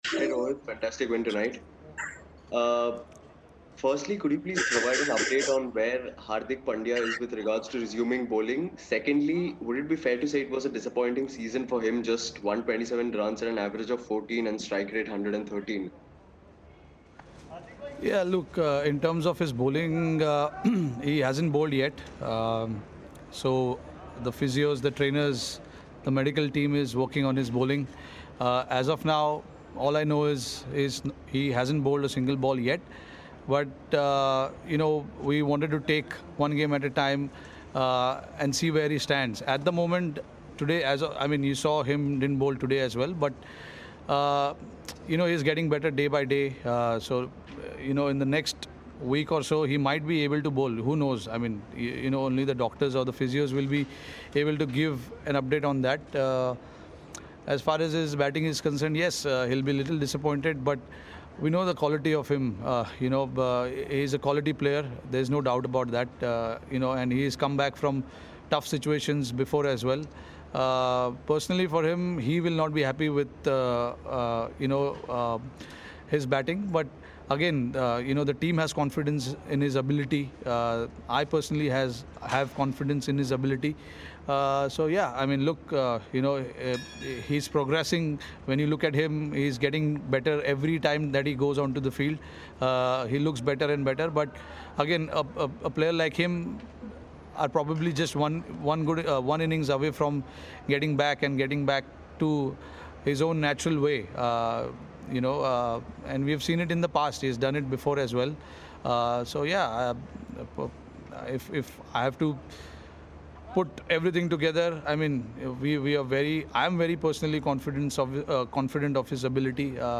Rohit Sharma of Mumbai Indians addressed the media after the game
Mohammad Nabi of Sunrisers Hyderabad and Rohit Sharma of Mumbai Indians addressed the media after the game.